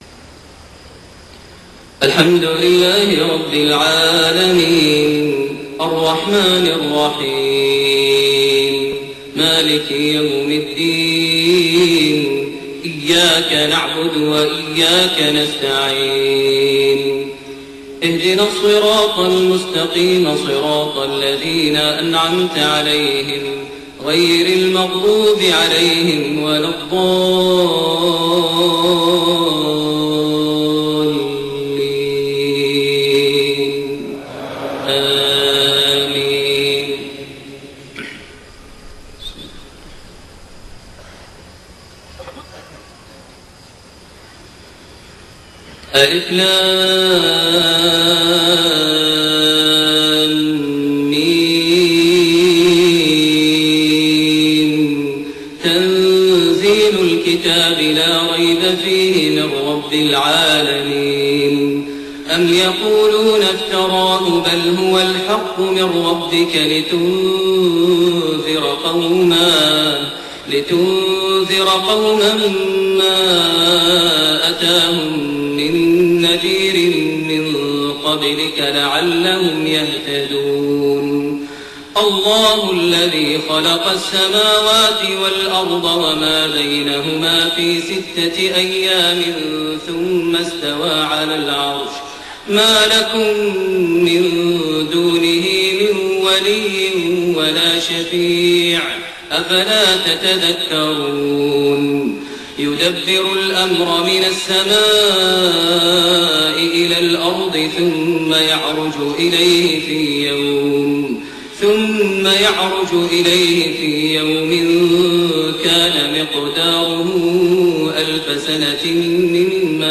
صلاة الفجر 3-4‐1431 سورة السجدة (الركعة الأولى) > 1431 هـ > الفروض - تلاوات ماهر المعيقلي